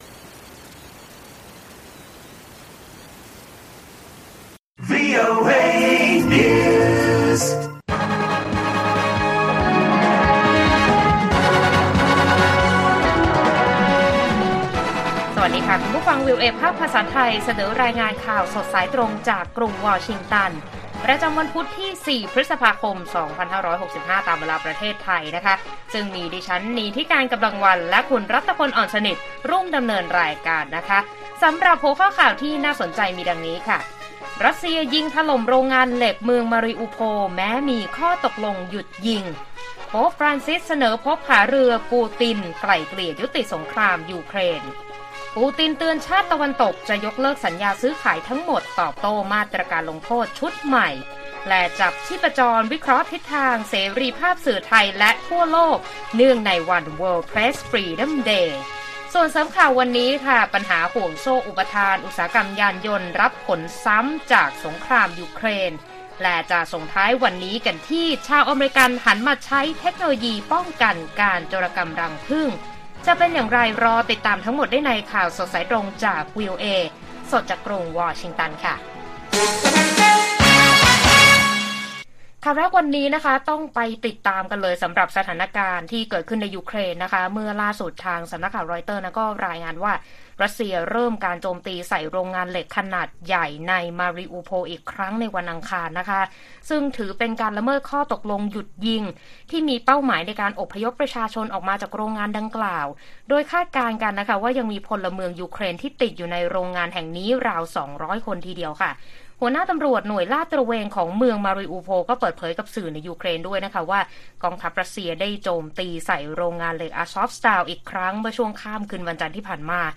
ข่าวสดสายตรงจากวีโอเอไทย 6:30 – 7:00 น. วันที่ 4 พ.ค. 2565